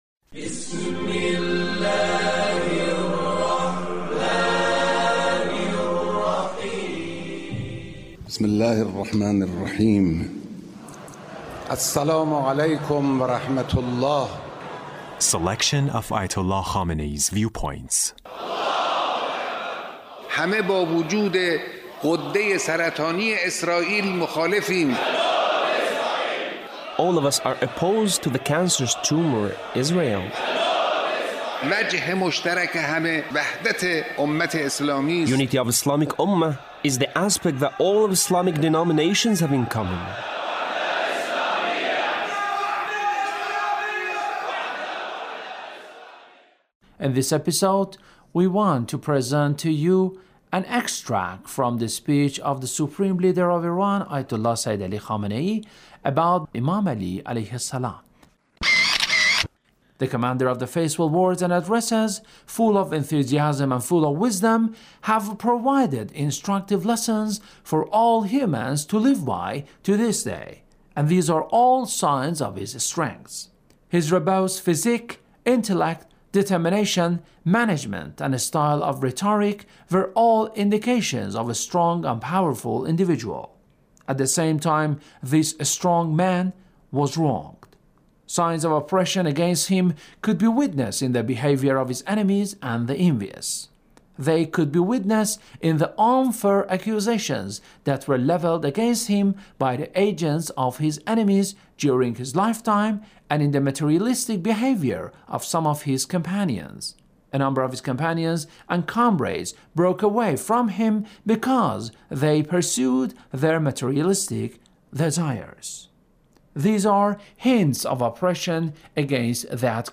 Leader's Speech (1928)